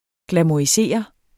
Udtale [ glamoɐ̯iˈseˀʌ ] eller [ glamuɐ̯iˈseˀʌ ]